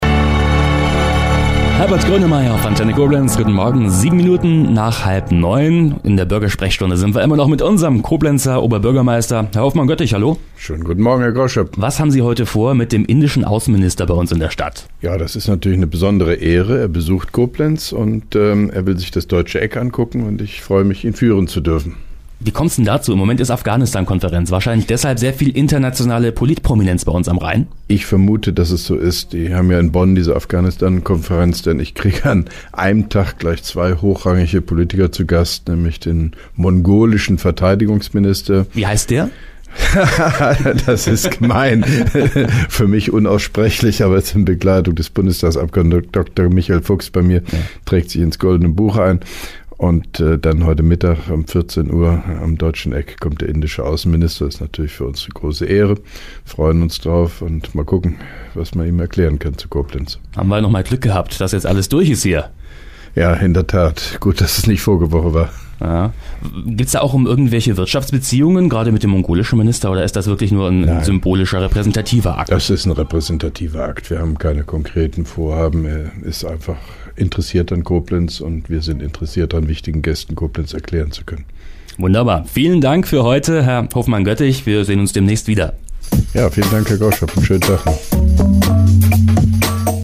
(3) Koblenzer Radio-Bürgersprechstunde mit OB Hofmann-Göttig 06.12.2011